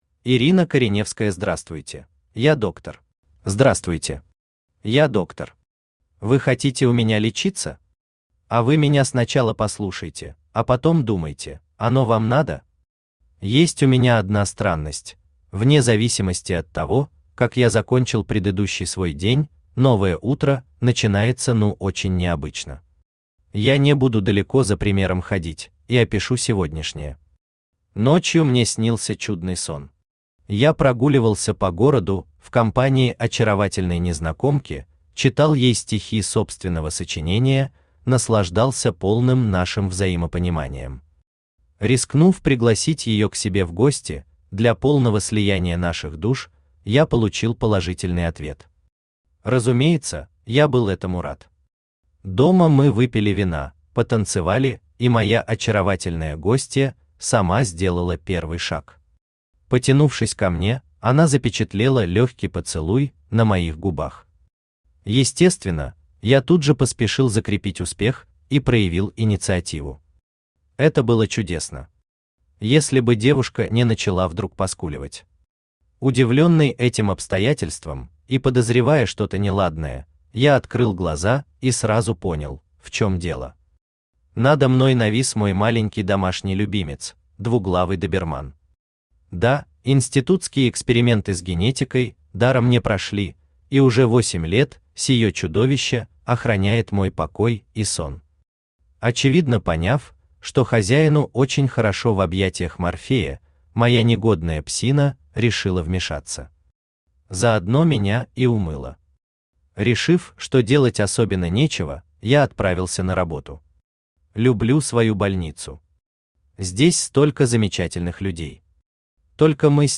Аудиокнига Здравствуйте.
Прослушать и бесплатно скачать фрагмент аудиокниги